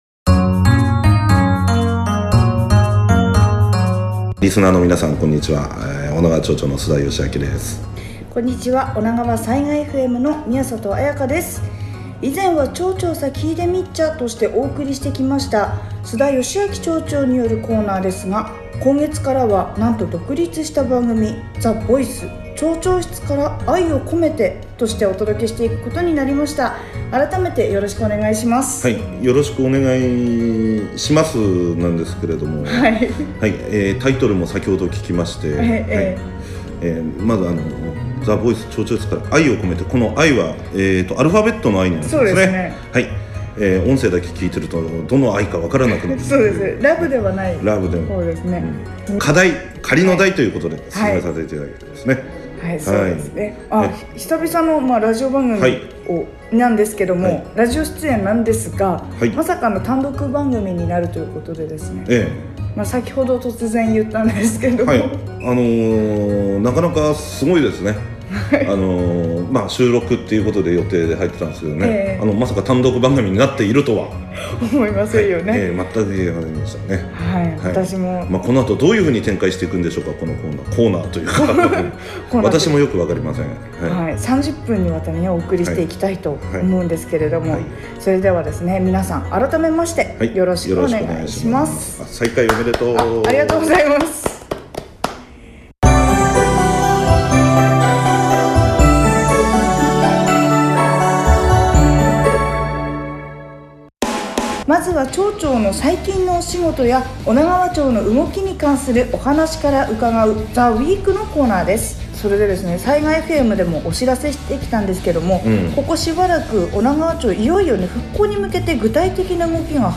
毎週、須田善明・女川町長が町長室から最近の町の動きや近況などを直接「声」で語ってくださるＴＨＥ VOICE（毎週金曜午後、他再放送）を町外在住リスナーの方のためにポッドキャストでも配信することになりました。
本編では町長自ら選曲した音楽もお届けしていますが、ポッドキャスト版では権利の都合で音楽はカットし、トーク部分のみとなります。